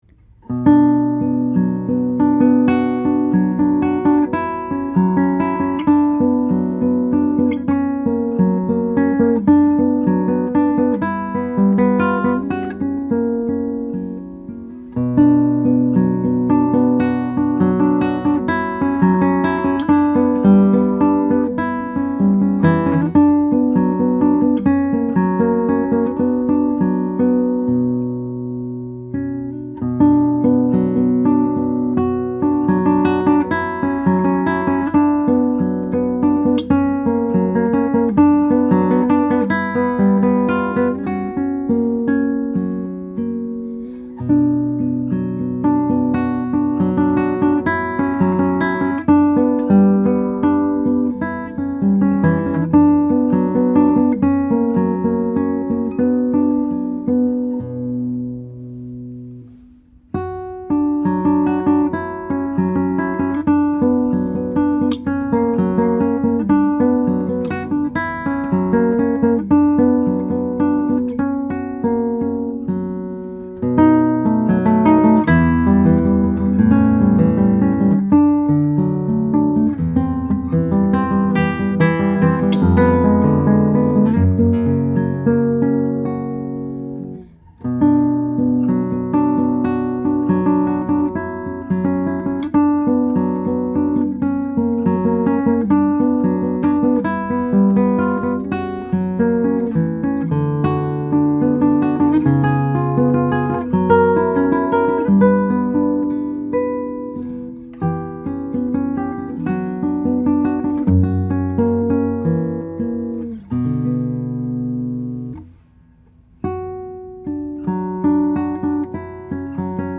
Here’s a mint condition 2008 Goodall Crossover, Nylon String Hybrid.
2008-Goodall-Crossover-Study-In-B-Minor-by-Fernando-Sor.mp3